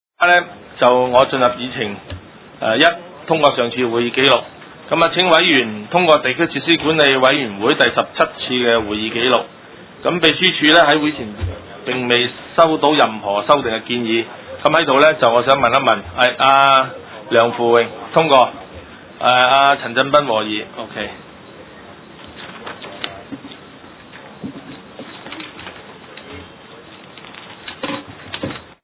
地區設施管理委員會地區工程專責小組特別聯合會議記錄
九龍觀塘同仁街6號觀塘政府合署3樓觀塘民政事務處會議室